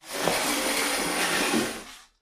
Shower curtain opens and closes. Open, Close Curtain, Shower